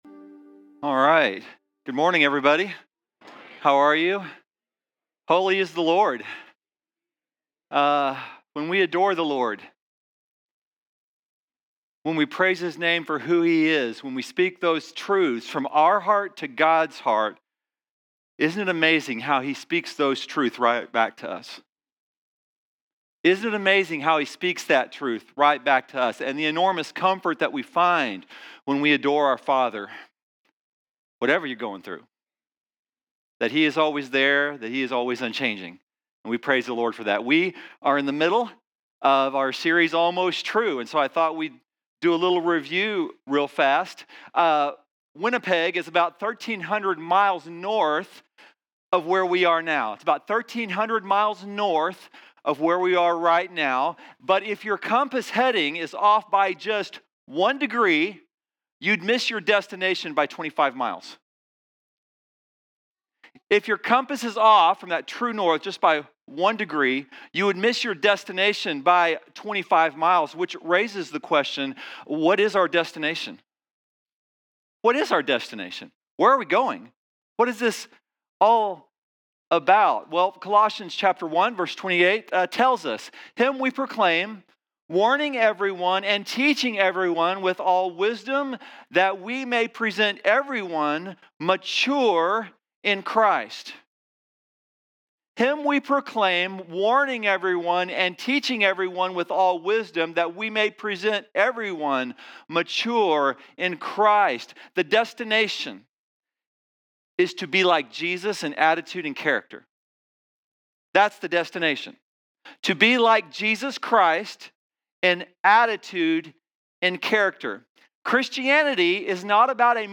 Play Rate Listened List Bookmark Get this podcast via API From The Podcast Weekly sermons from Chisholm Summit Community Church in Burleson, Tx.